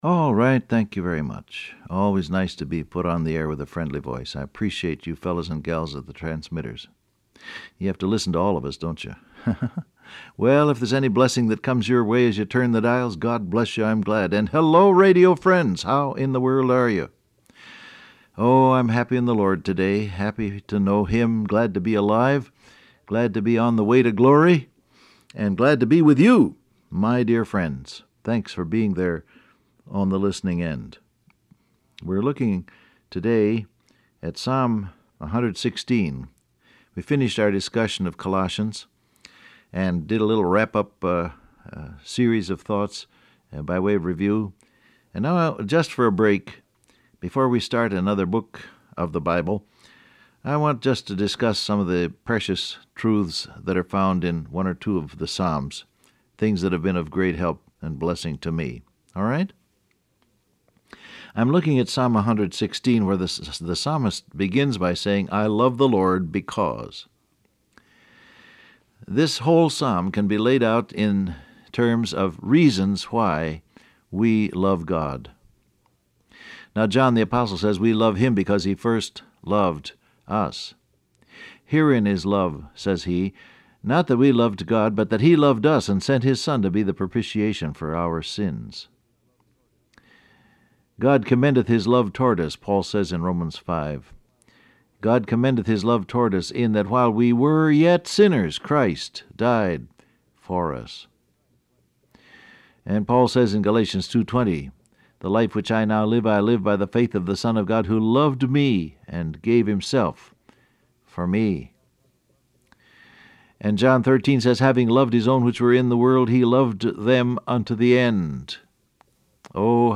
Download Audio Print Broadcast #6314 Scripture: Psalm 116:1-2 Topics: Prayer , Audible , Love For God , Walk In Love , Supplication Transcript Facebook Twitter WhatsApp Alright, thank you very much.